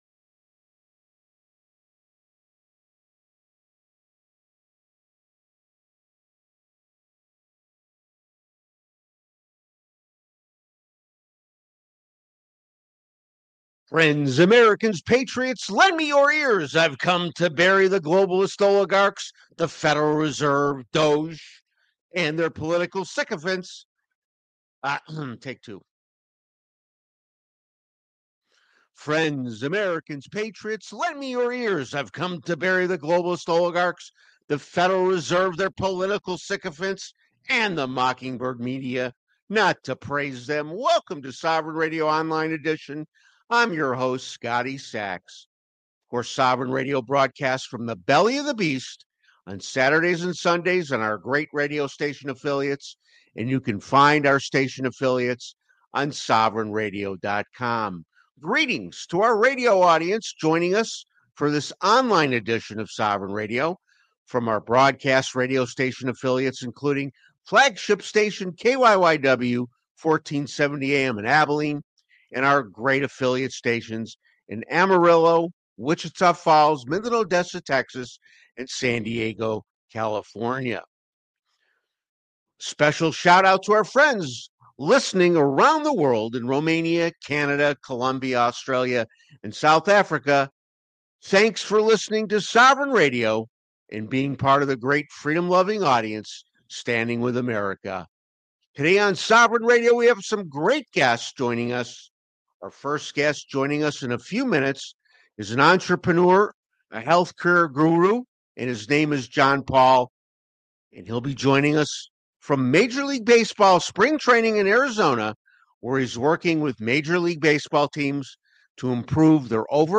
Live Shows